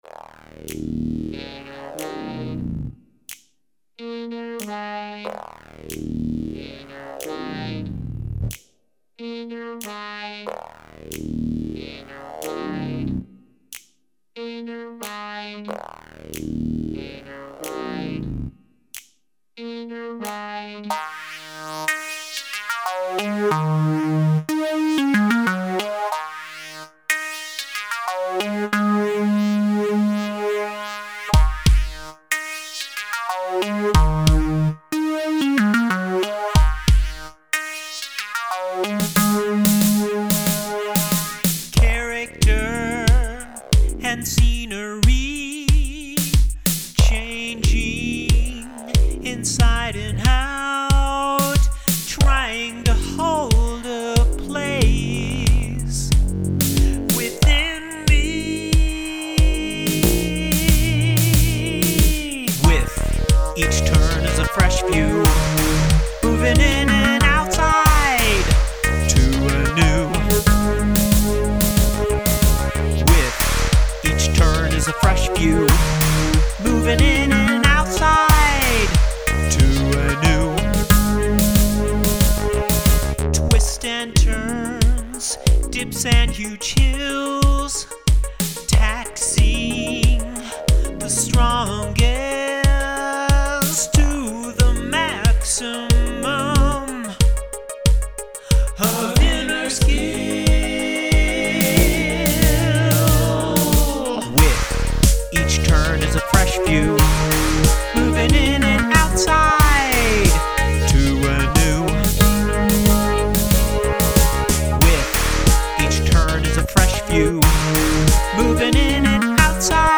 I heard a lot of cool synth voices that I wanted to put in.
I felt at this point I had enough to make a stereo mix.
I also added a separate keyboard in verse #3.